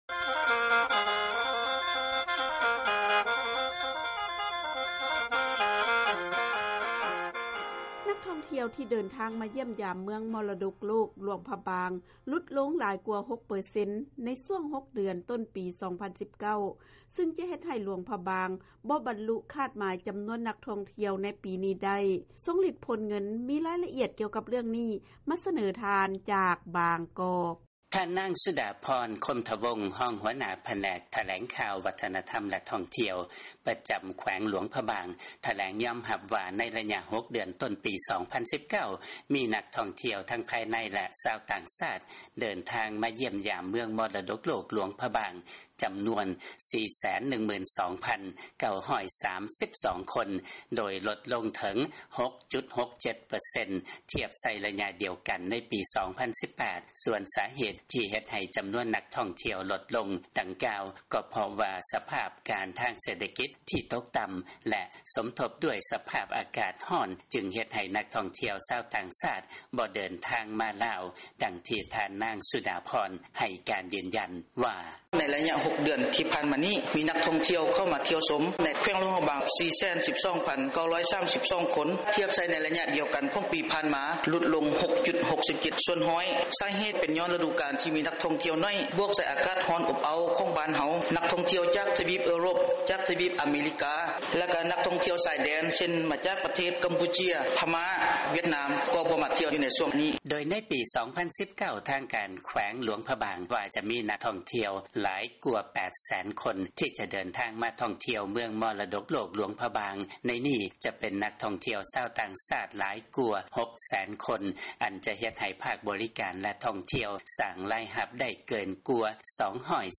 ເຊີນຟັງລາຍງານ ນັກທ່ອງທ່ຽວ ເດີນທາງມາຢ້ຽມຢາມ ເມືອງມໍລະດົກໂລກ ຫຼວງພະບາງ ຫລຸດລົງ ຫຼາຍກວ່າ 6 ເປີເຊັນ